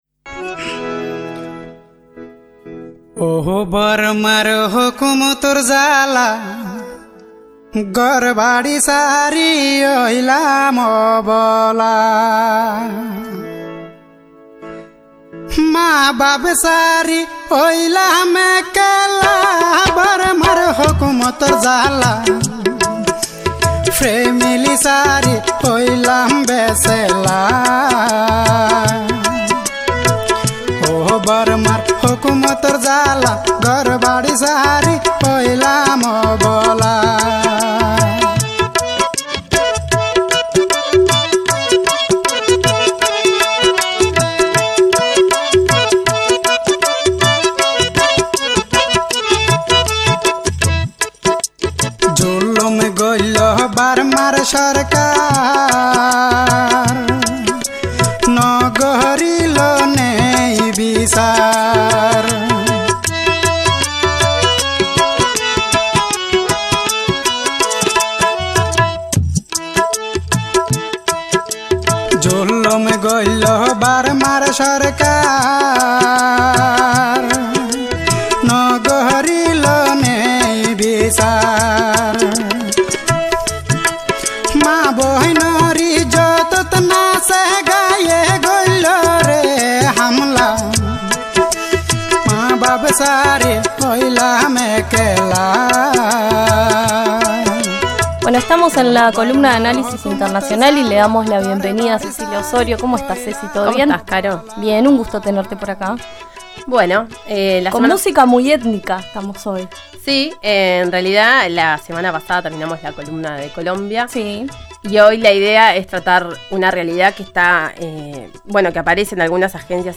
En la columna conversamos con Saskia Sassen, socióloga holandesa, docente de la Universidad de Columbia, quien explica cómo el desarrollo económico es un factor importante en estos procesos de expulsión.